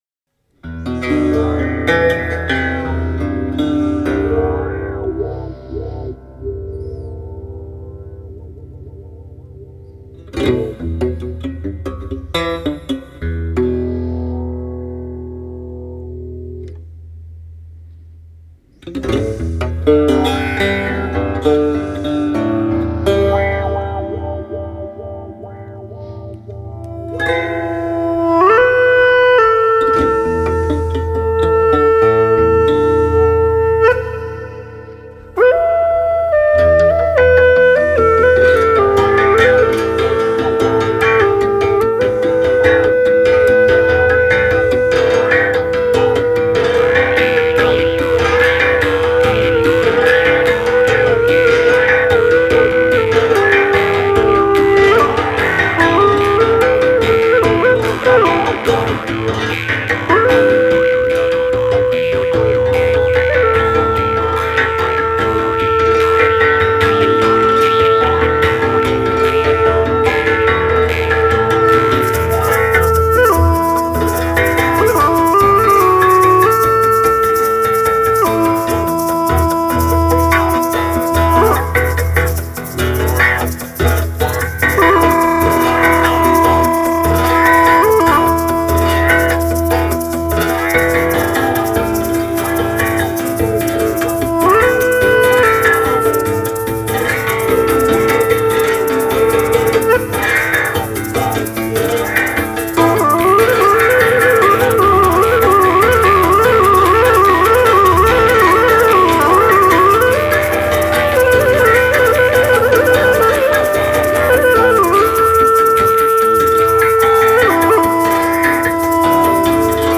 Gendung (Indonesian Drums)
Xylopt and drum kit
flute